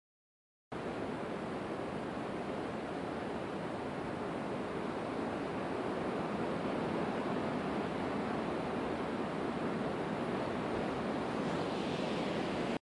Wind chimes on a very windy day
描述：4 sets of wind chimes made from silverware on a very windy day. Recorded in stereo with Zoom H4 and EQ'd with a low cut filter, looped 4x to extend the length.
标签： wind windchimes trees windy gusts h4 windchimes chimes
声道立体声